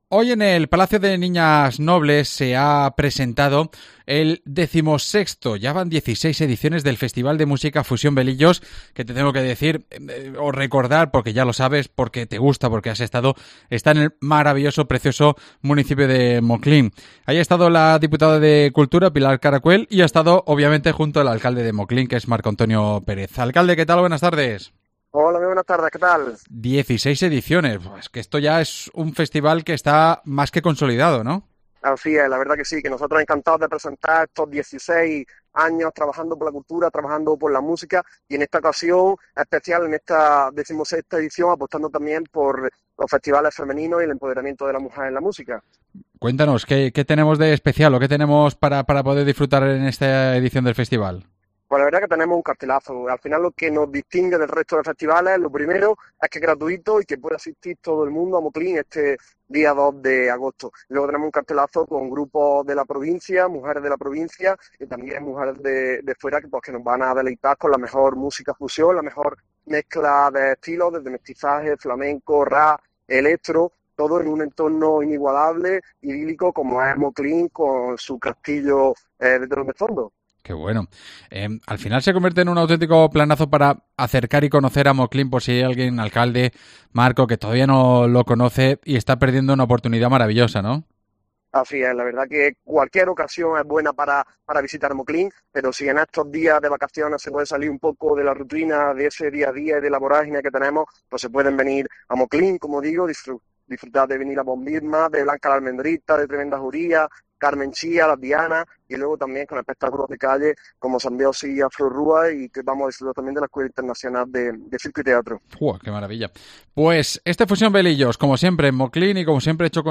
AUDIO: Marco Antonio Pérez es su alcalde y hablamos con él de la cita del próximo 2 de agosto